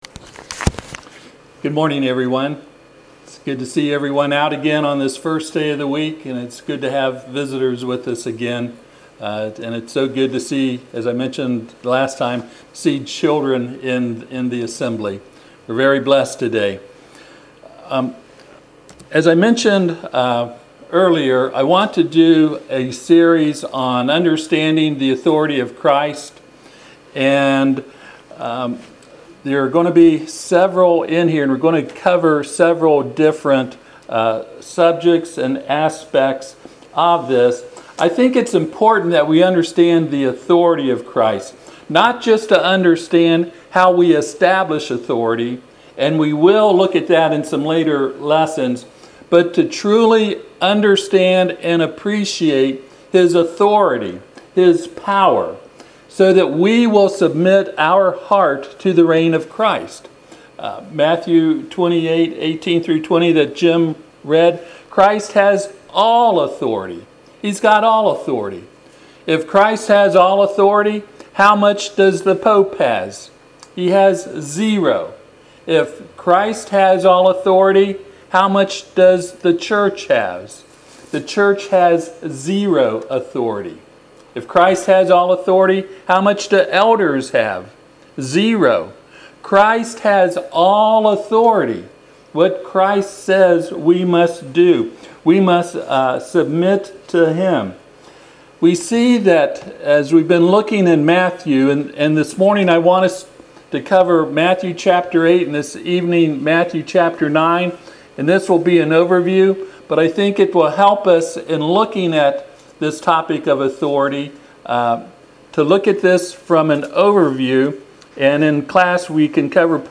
Passage: Matthew 8:1-34 Service Type: Sunday AM Topics